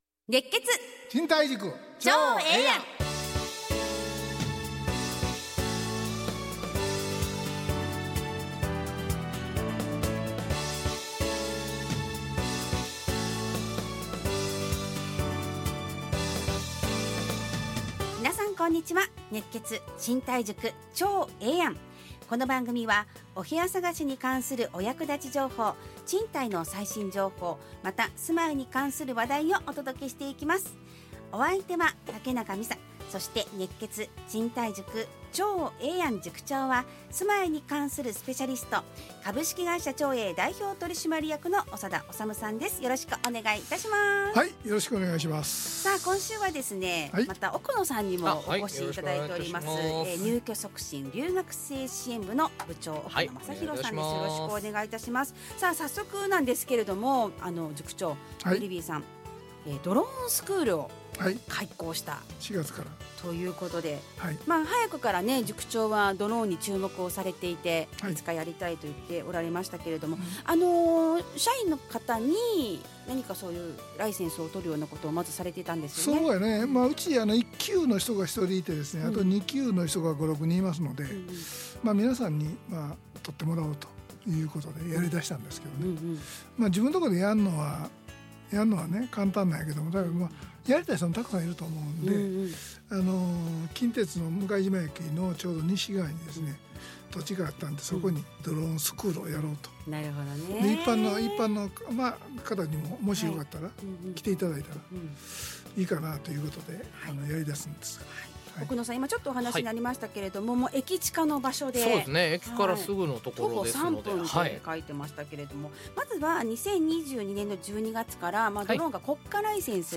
ラジオ放送 2025-05-30 熱血！